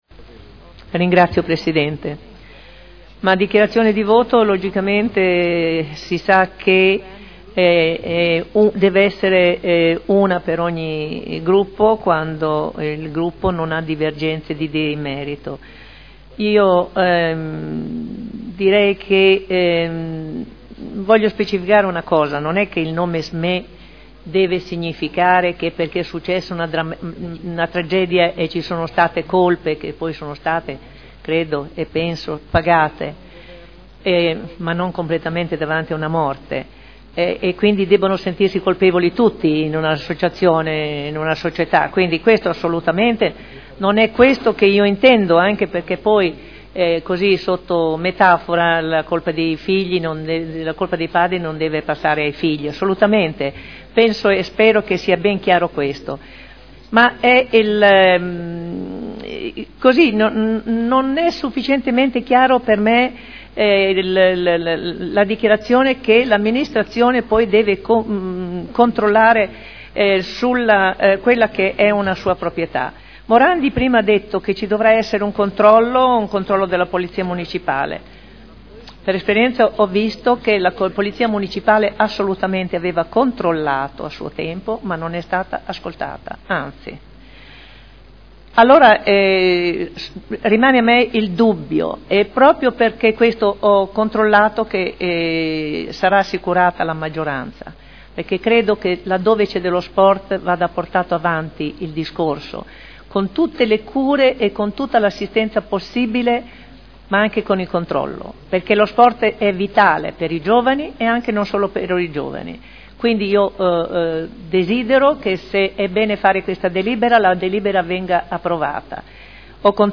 Seduta del 30/05/2011. Dichiarazione di voto su proposta di deliberazione: Proroga e integrazione del diritto di superficie assegnato a Equipenta Srl per sport equestri in Via Contrada
Audio Consiglio Comunale